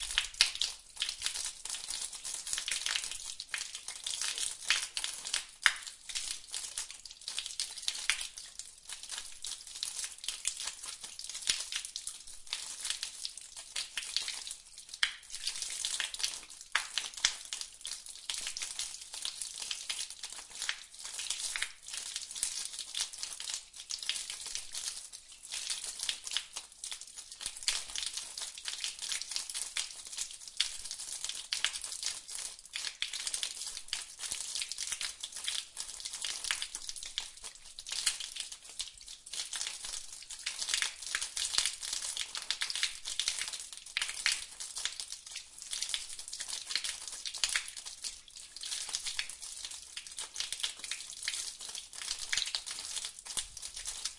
FP 排水系统 滴水
描述：水非常迅速地滴入一个混凝土排水渠。
标签： 下水道
声道立体声